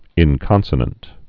(ĭn-kŏnsə-nənt)